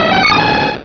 Cri de Scarhino dans Pokémon Rubis et Saphir.